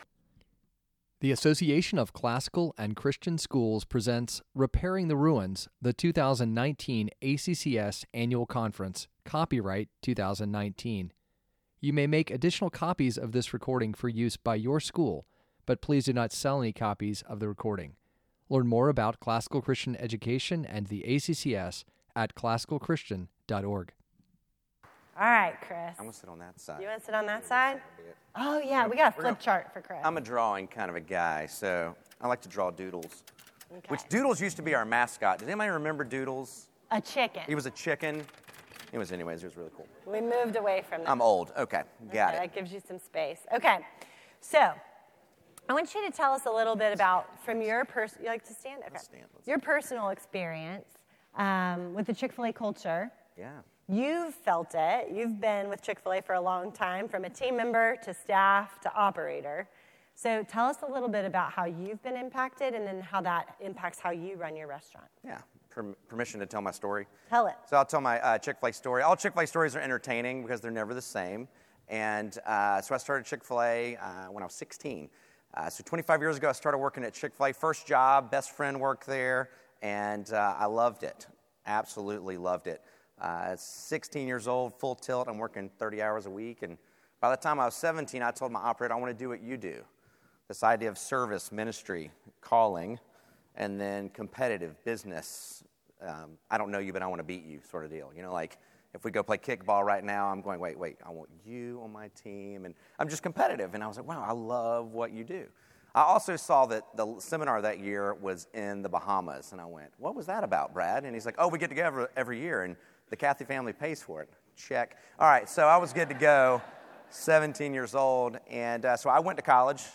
2019 Leaders Day Talk | 48:56 | Fundraising & Development, Leadership & Strategic, Marketing & Growth, Training & Certification